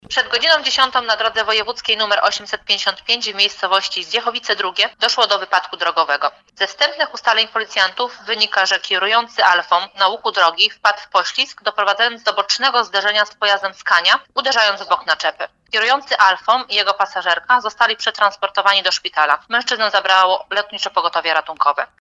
Na zakręcie samochód osobowy zderzył się z samochodem ciężarowym. Mówi oficer prasowa